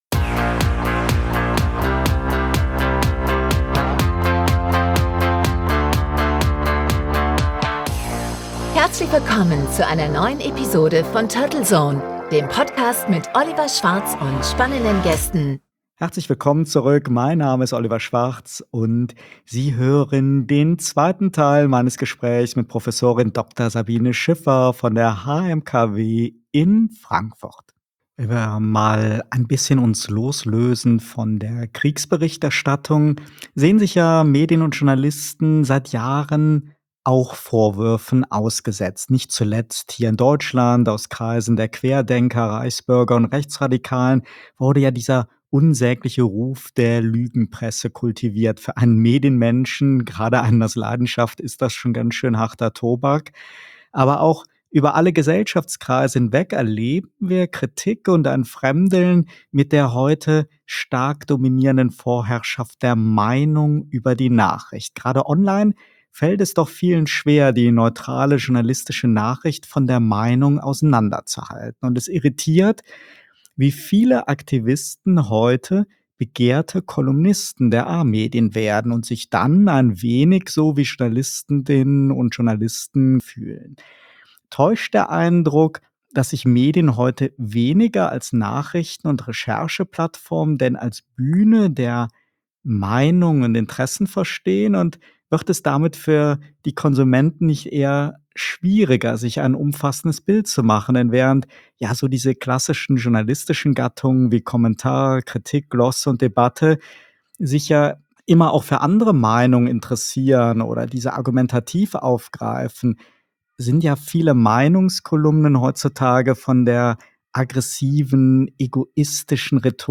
Die Aufzeichnung des Remote-Interviews erfolgte "live on tape" am 11.07.2022 um 17.00 Uhr.